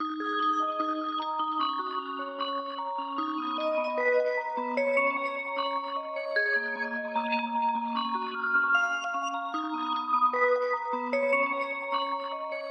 环境钟声
Tag: 151 bpm Trap Loops Bells Loops 2.14 MB wav Key : Dm FL Studio